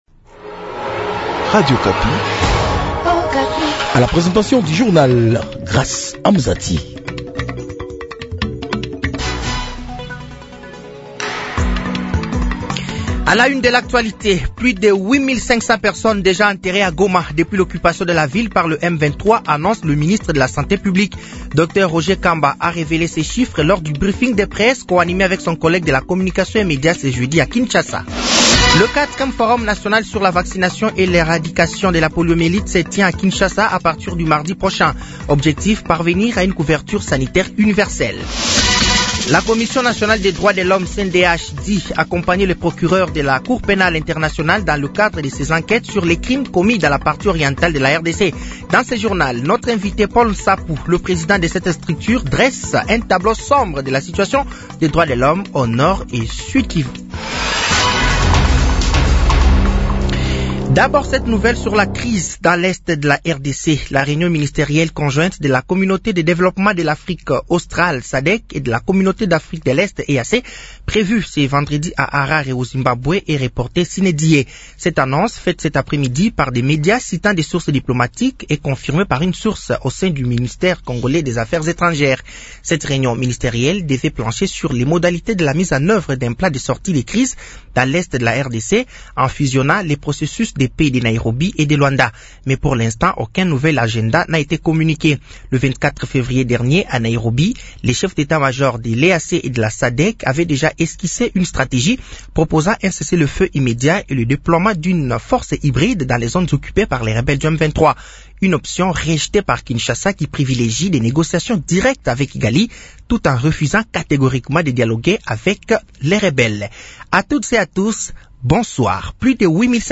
Journal français de 18h de ce vendredi 28 février 2025